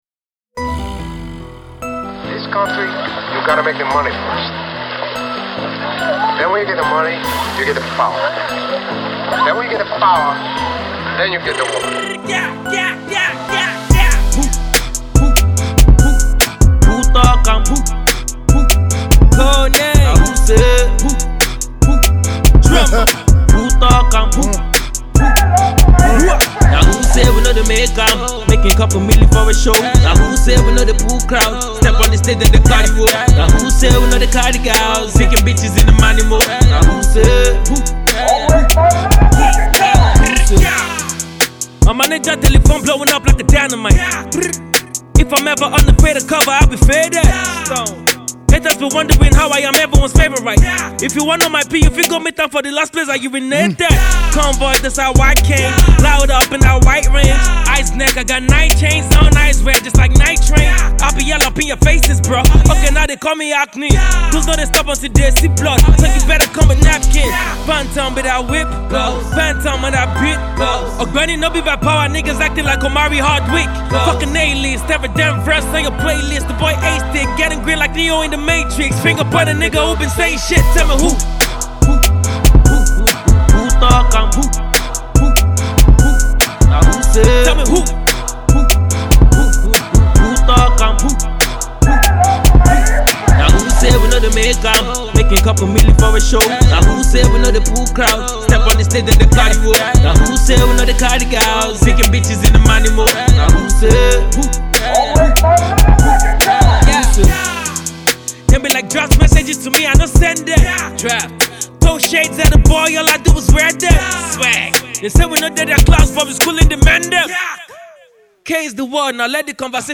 The king of trap music in Nigeria